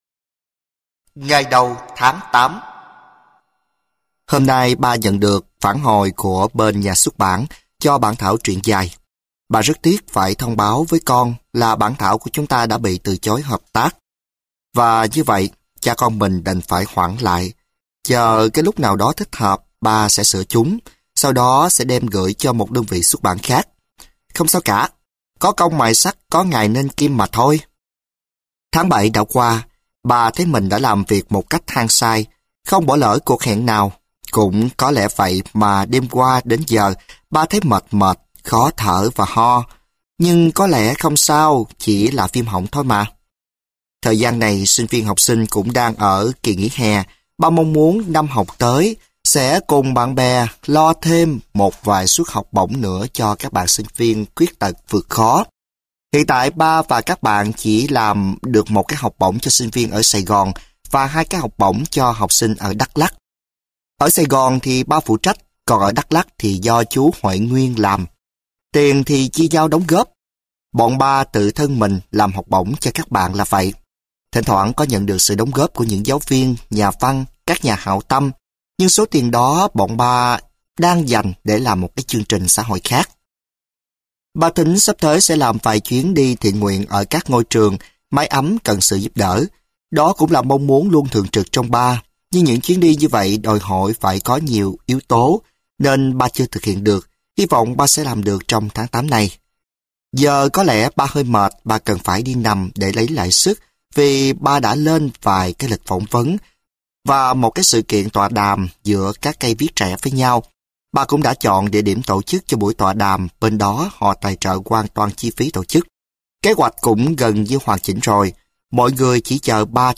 Sách nói Con Đến Như Một Phép Màu - Lê Hữu Nam - Sách Nói Online Hay